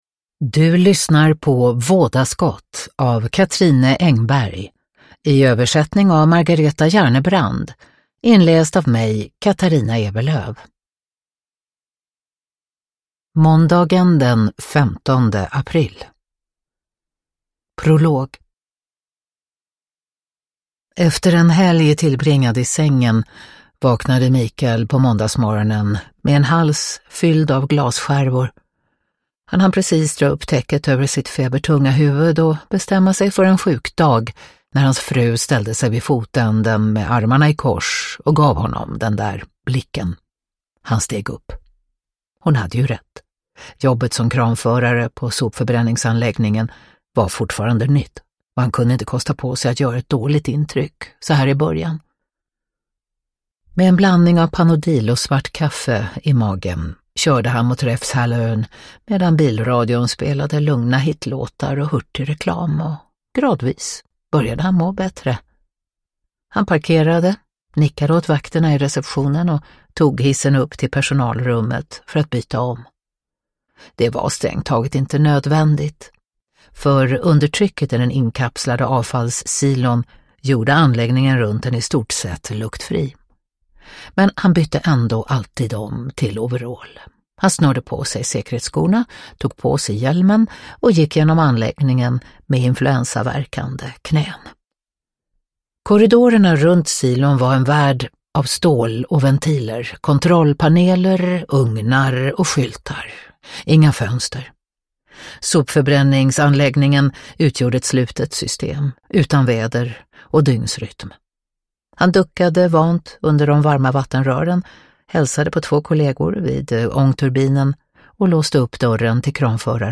Vådaskott – Ljudbok – Laddas ner
Uppläsare: Katarina Ewerlöf